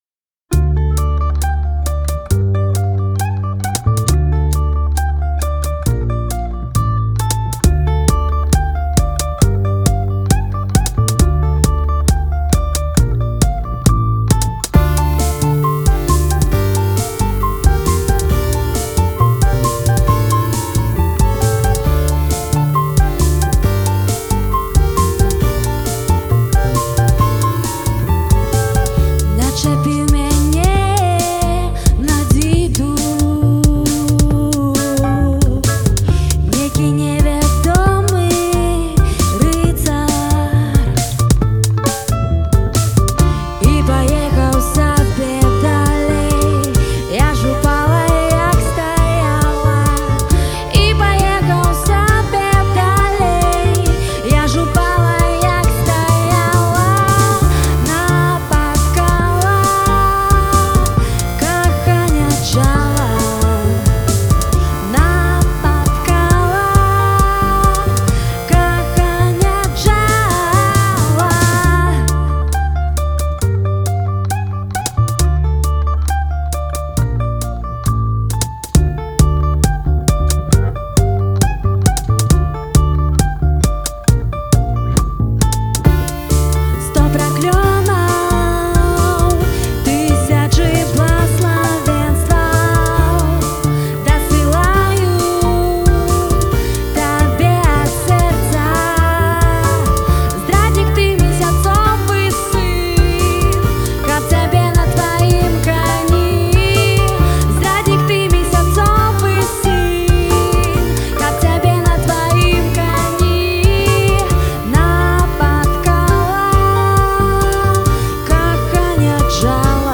жывы запіс песьні